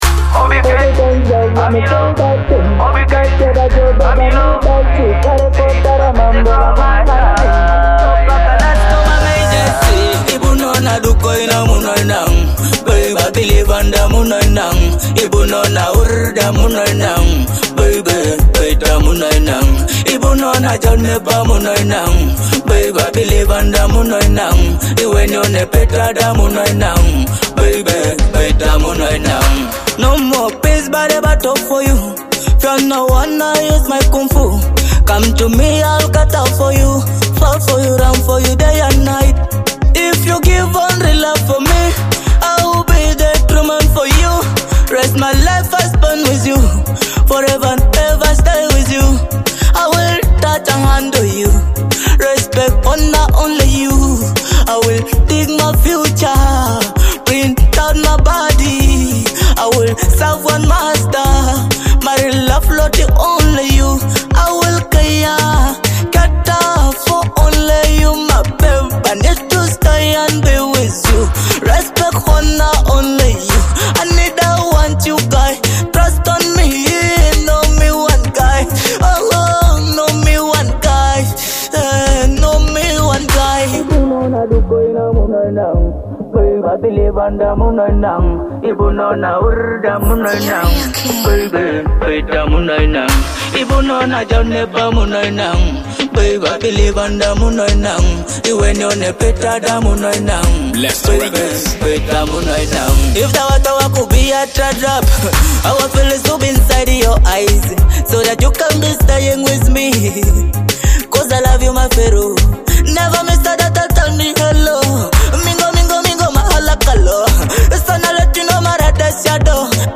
a soulful Teso love song expressing deep hope
a heartfelt Teso love song
Through warm melodies and sincere vocals